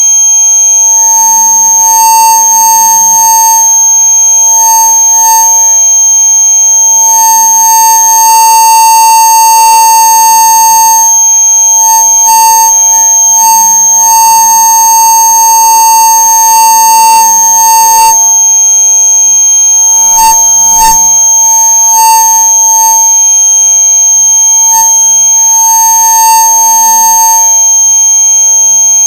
bespoke hol speaker with 15K tone and rec jack
Adjust your volume low to start with so you don’t jump off your seat when you hear this.
bespoke-hol-speaker-with-15K-tone-and-rec-jack.mp3